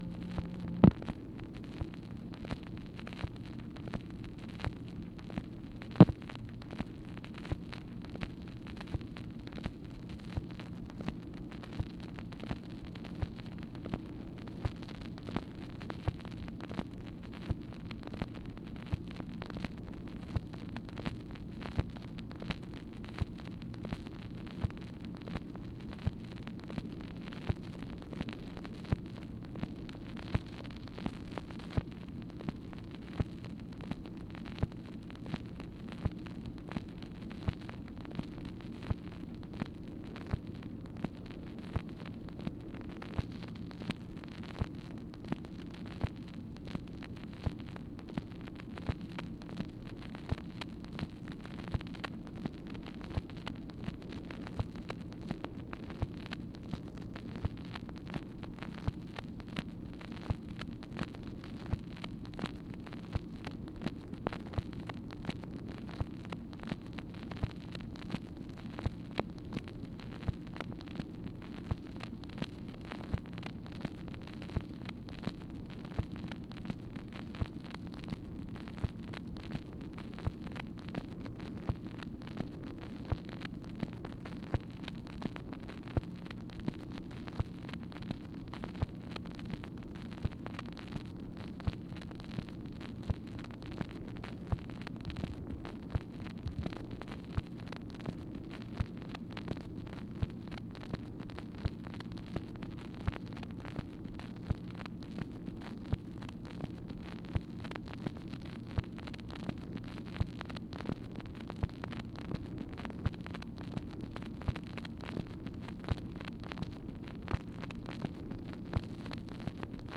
MACHINE NOISE, March 21, 1964
Secret White House Tapes | Lyndon B. Johnson Presidency